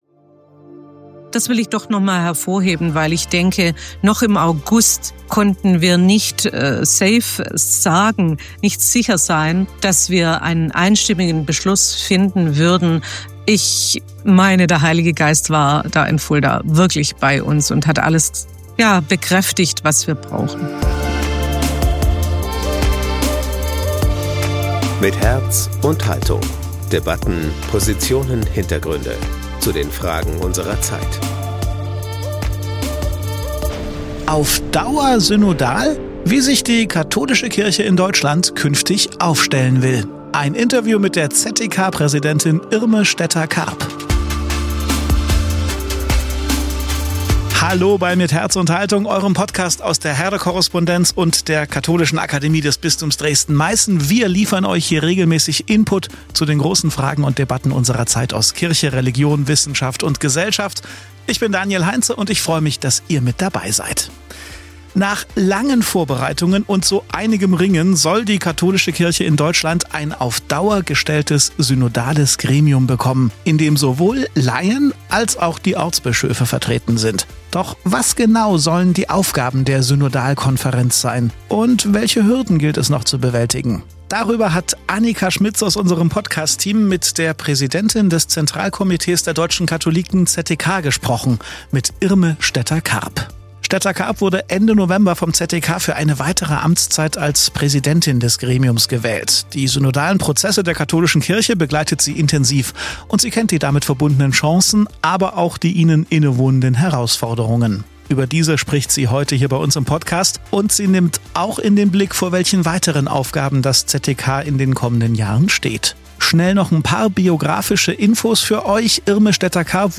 Doch was genau sollen die Aufgaben der Synodalkonferenz sein und welche Hürden gibt es noch zu bewältigen? Darüber haben wir mit der Präsidentin des Zentralkomitees der deutschen Katholiken (ZdK), Irme Stetter-Karp, gesprochen.